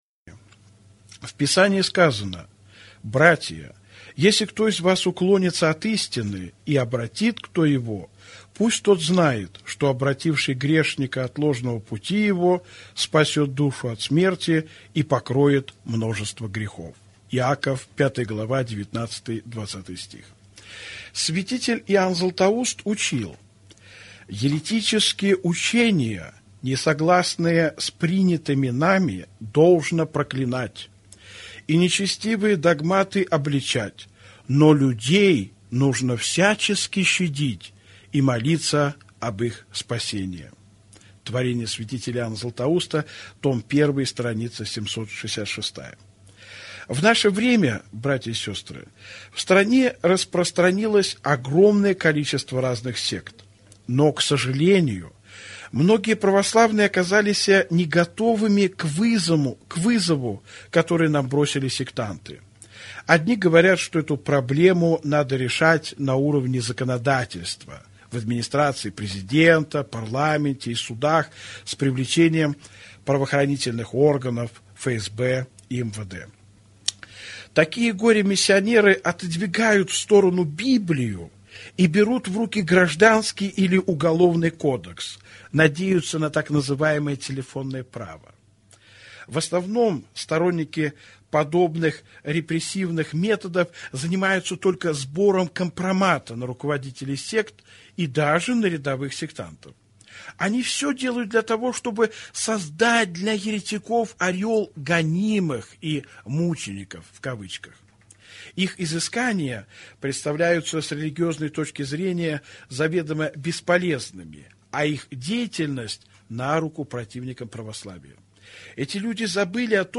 Аудиокнига Как защитить Святое Православие с Библией в руках | Библиотека аудиокниг